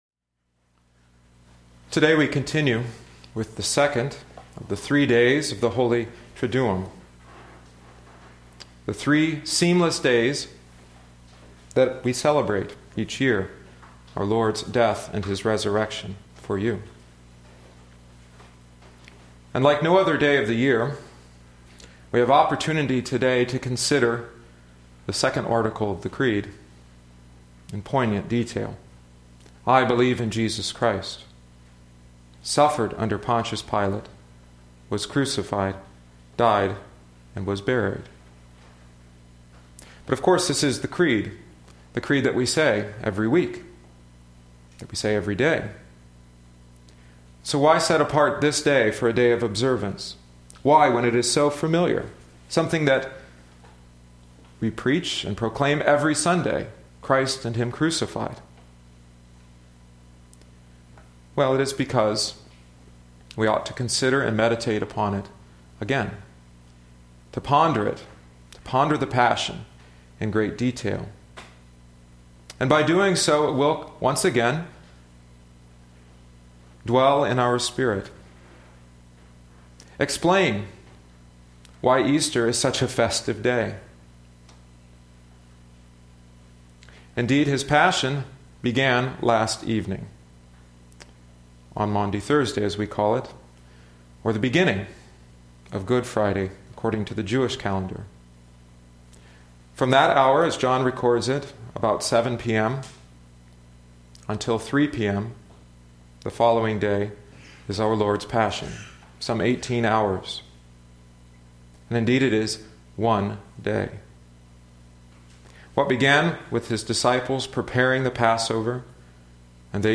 (I am heavily indebted to Luther’s 1533 Good Friday House Postil for this sermon.)